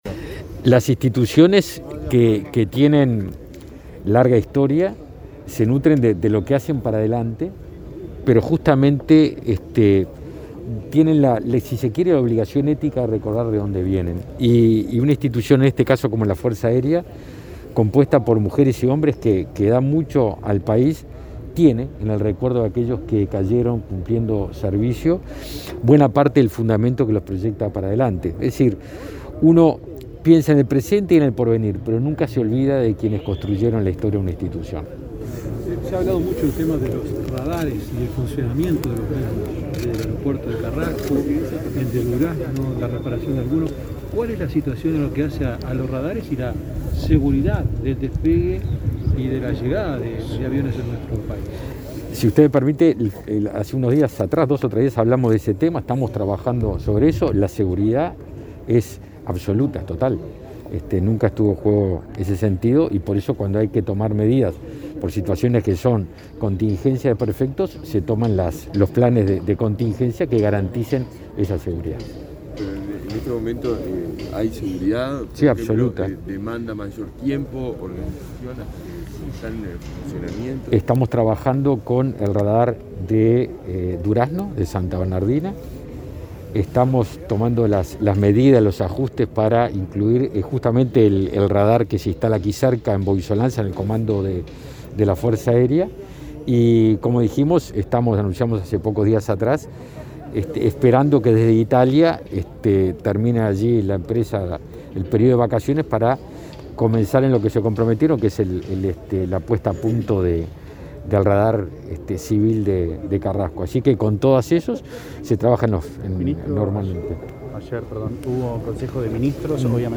Declaraciones a la prensa del ministro de Defensa Nacional, Javier García, en el Día de los Mártires de la Aviación Militar